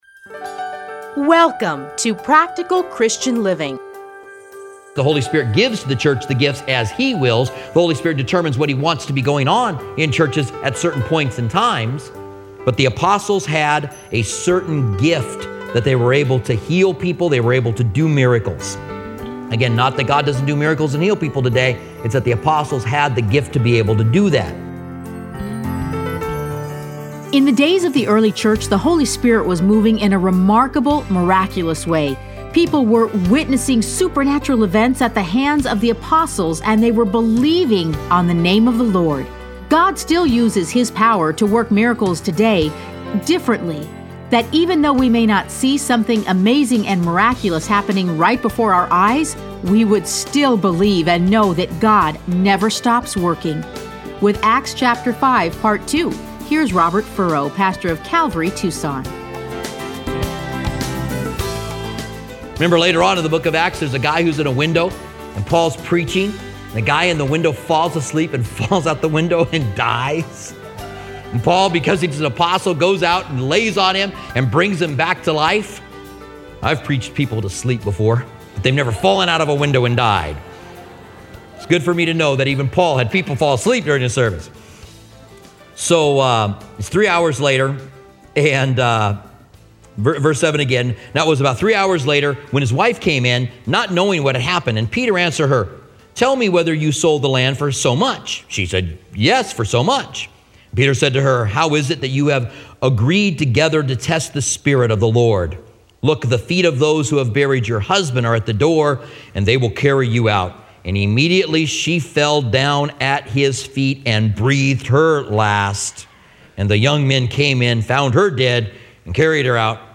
Listen to a teaching from Acts 5.